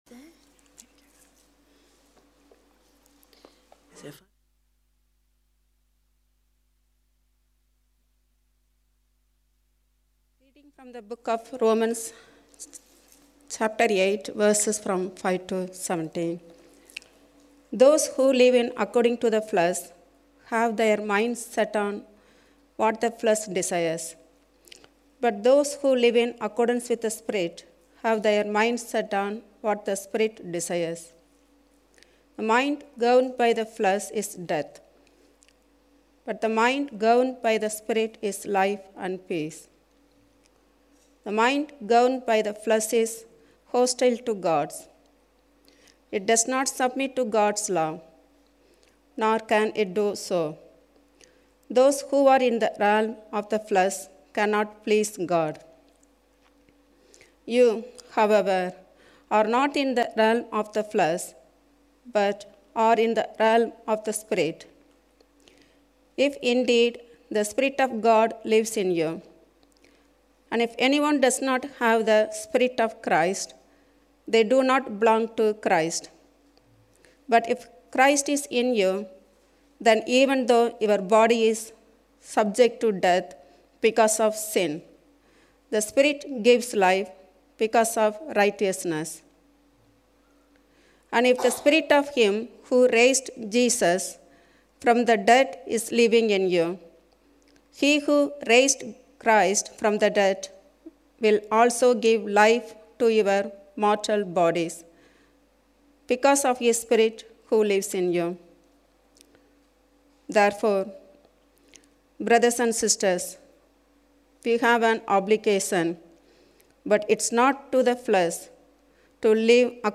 A talk from the series "Our Identity in Christ."